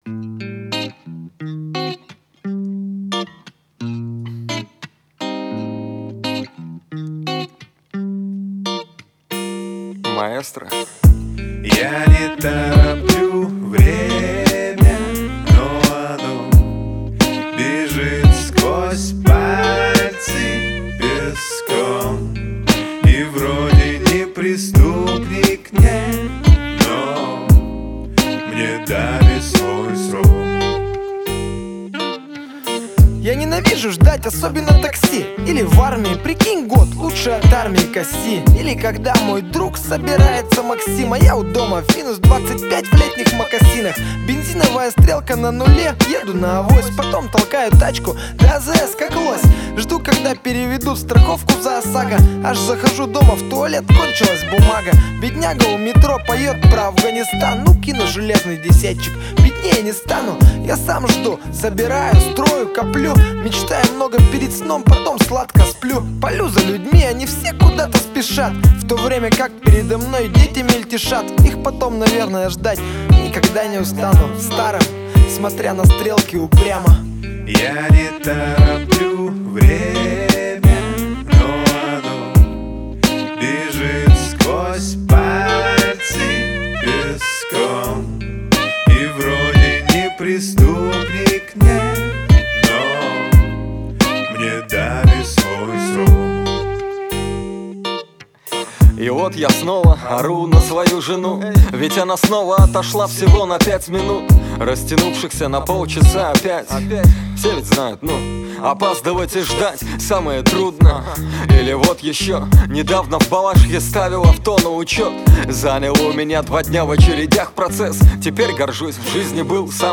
Категория: Рэп (Хип-хоп)